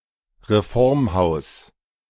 Reformhaus (German pronunciation: [ʁeˈfɔʁmˌhaʊ̯s]
De-Reformhaus.ogg.mp3